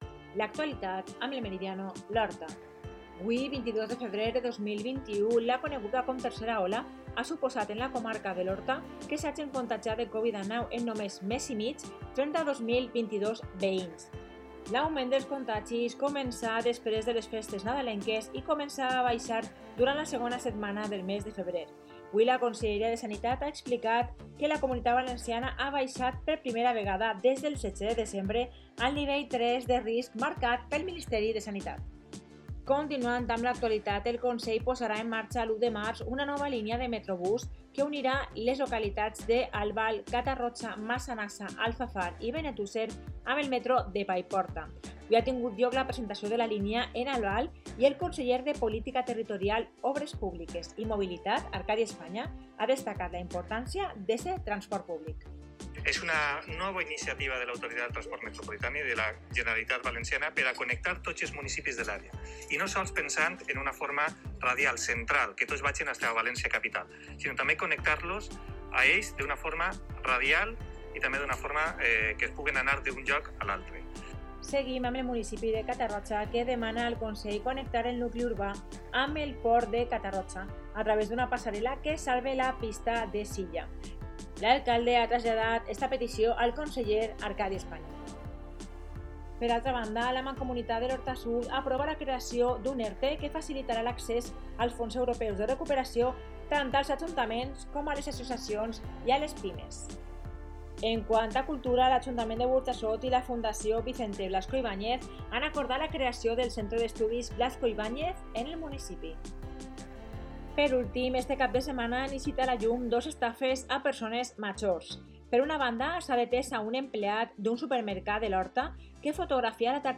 Informativo 22/2/21: